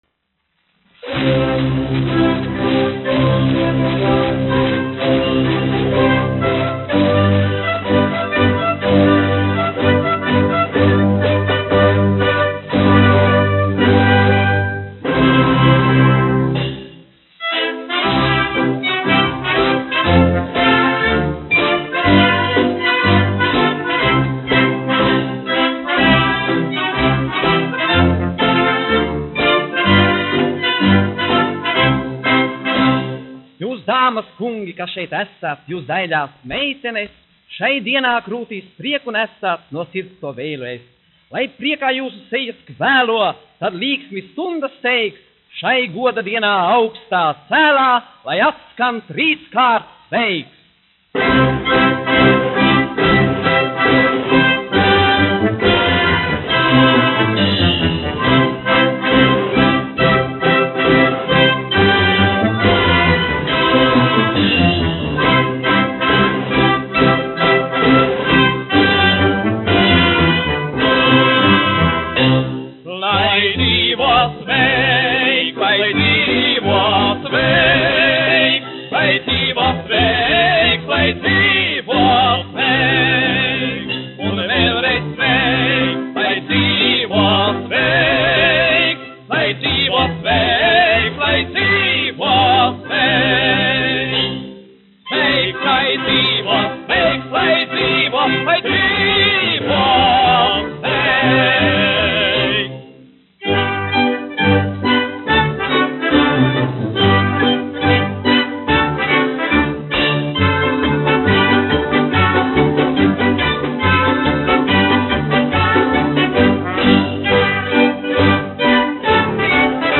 1 skpl. : analogs, 78 apgr/min, mono ; 25 cm
Monologi ar mūziku
Populārā mūzika -- Latvija
Skaņuplate
Latvijas vēsturiskie šellaka skaņuplašu ieraksti (Kolekcija)